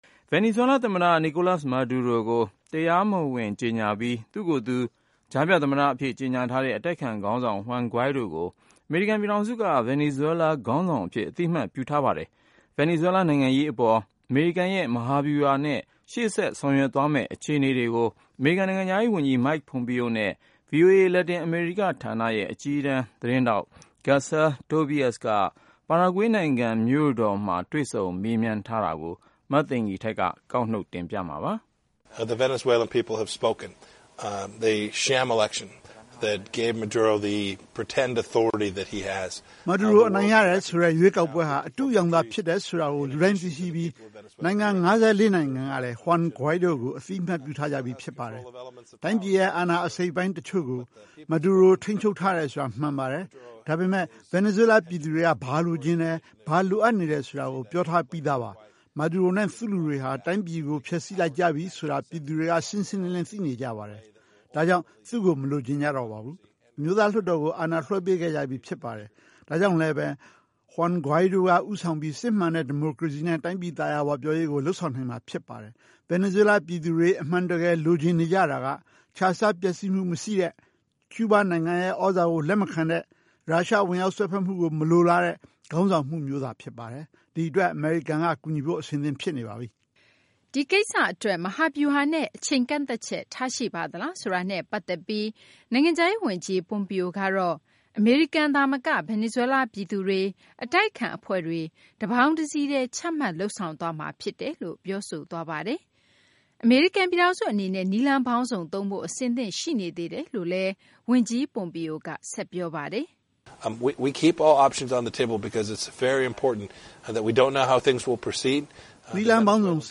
ပါရာဂွေးနိုင်ငံ မြို့တော် Asuncion မှာ တွေ့ဆုံ မေးမြန်းထားပါတယ်။